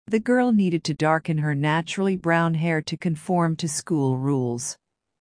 【ややスロー・スピード】